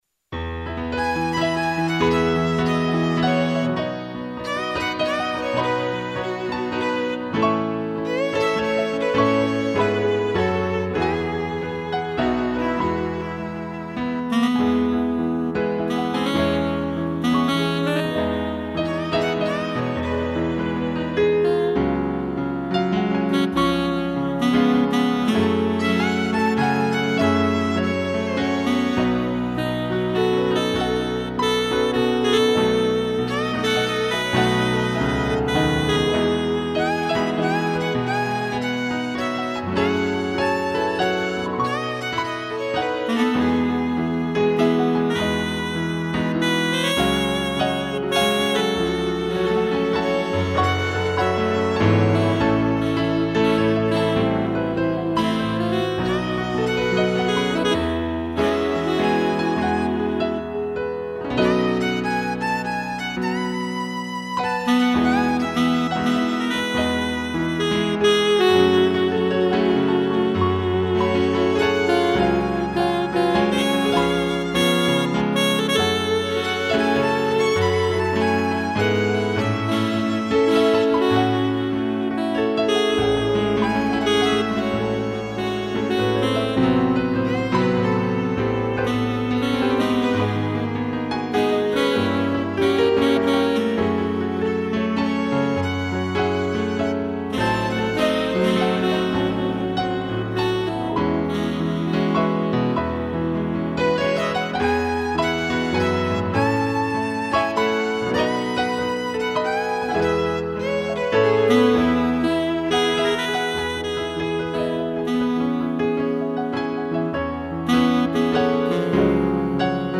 2 pianos e violino
solo sax